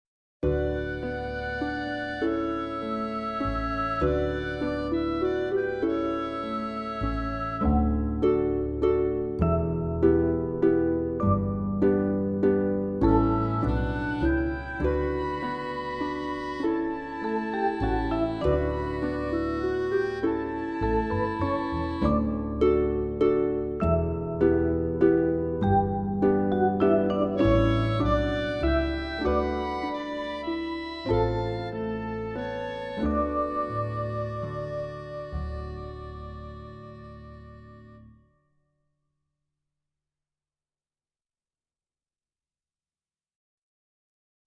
VS London Eye (backing track)